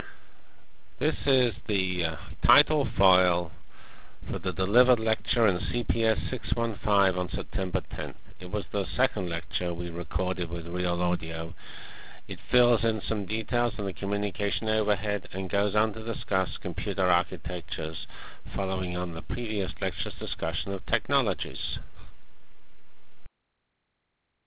Lecture of September 10 - 1996